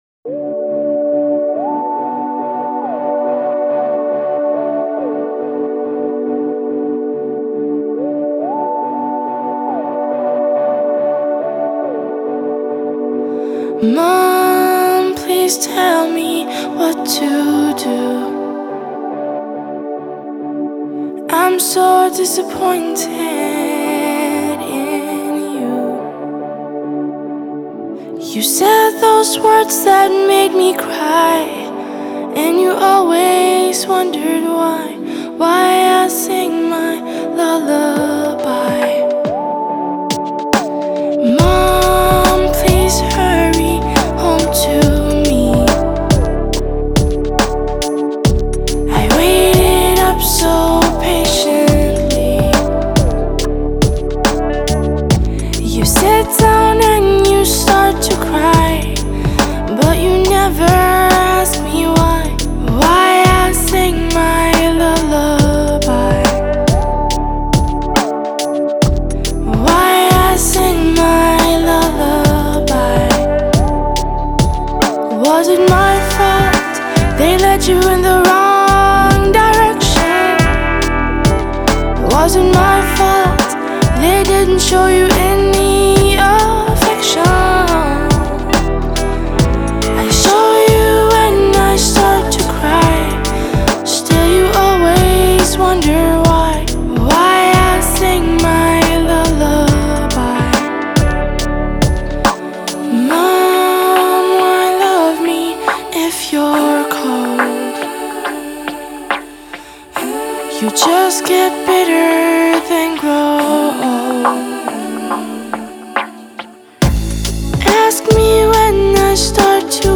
장르: Rock
스타일: Acoustic, Pop Rock